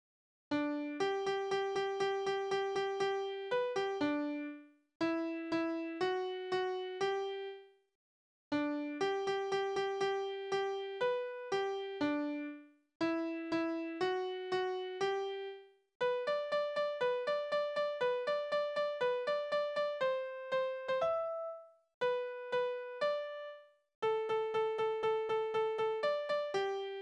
« O-10606-1 » Die Leinenweber haben Berufslieder: Leinenweberzunft Die Leinenweber haben eine saubere Zunft.
Tonart: G-Dur Taktart: 2/4 Tonumfang: große None Besetzung: vokal Externe Links: Sprache: hochdeutsch eingesendet (o. D.)